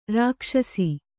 ♪ rākṣasi